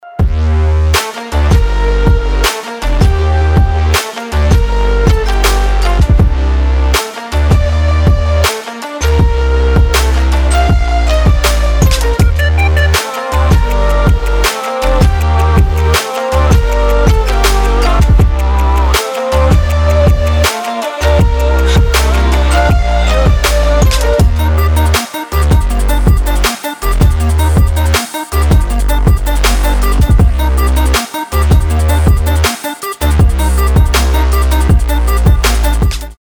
Electronic
Trap
future bass
Красивая мелодия без слов